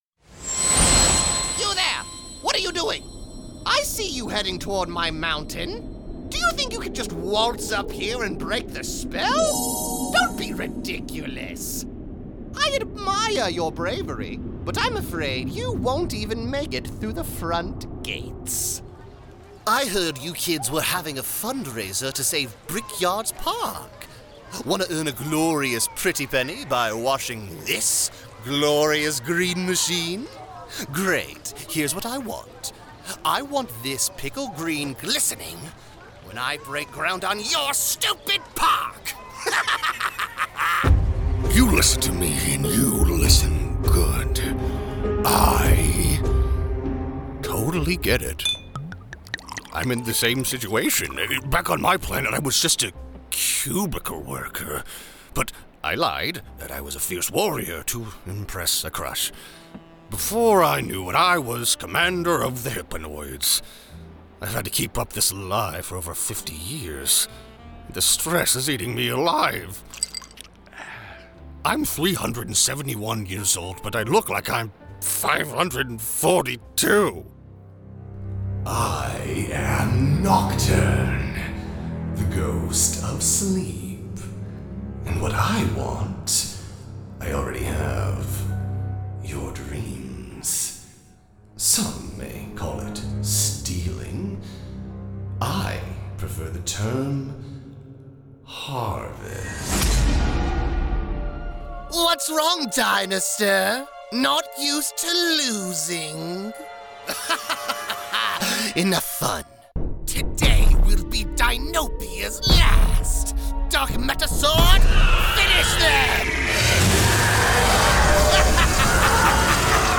Character Demo